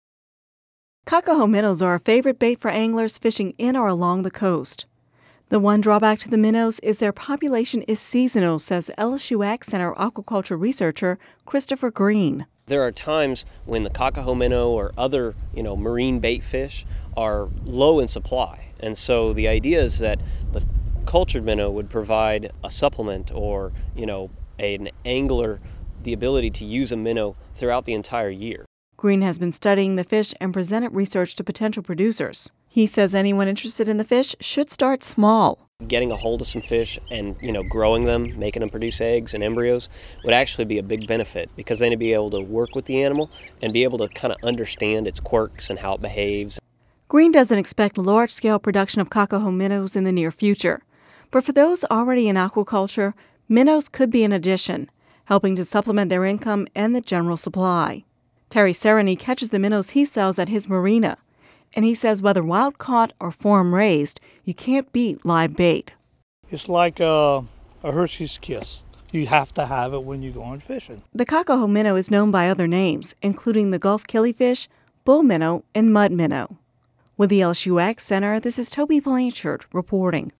(Radio News 11/01/10) Cocahoe minnows are a favorite live bait for anglers fishing in or along the Louisiana coast.